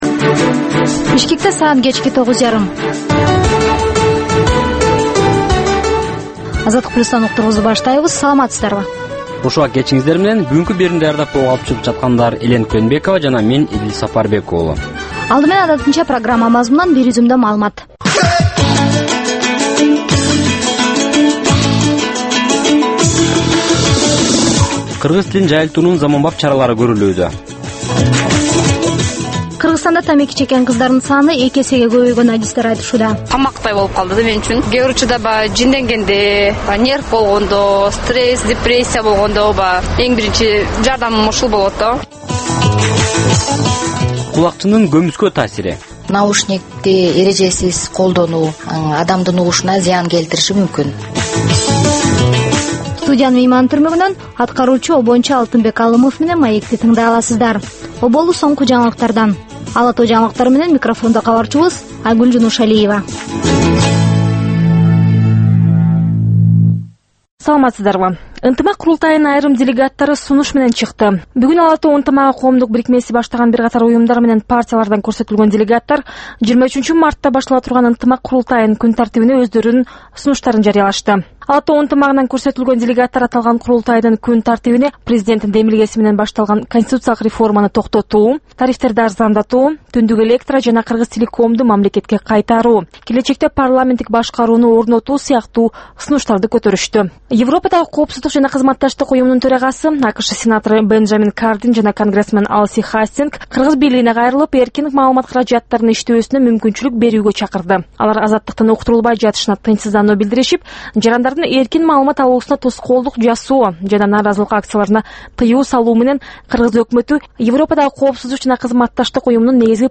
Бул жаштарга арналган кечки үналгы берүү жергиликтүү жана эл аралык кабарлардан, репортаж, маек, баян жана башка берүүлөрдөн турат. "Азаттык үналгысынын" бул жаштар берүүсү Бишкек убактысы боюнча саат 21:30дан 22:00ге чейин обого чыгат.